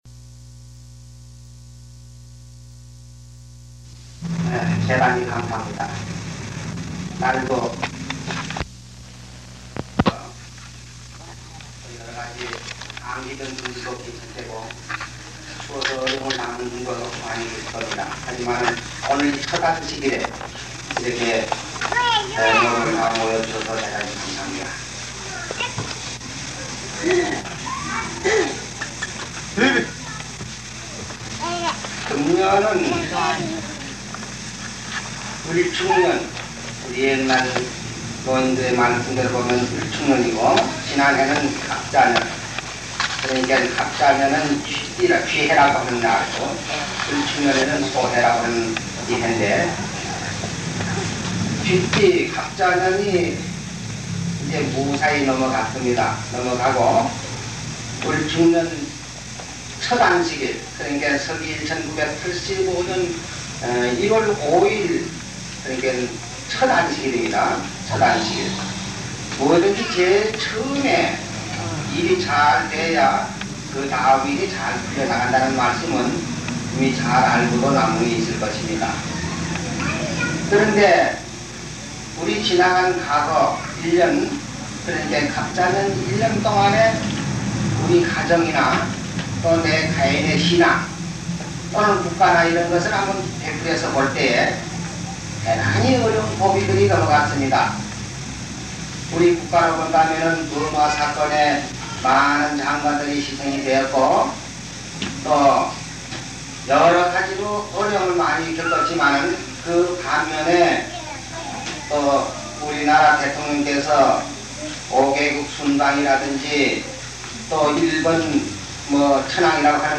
사람 낚는 어부(1985년1월5일첫안식일설교)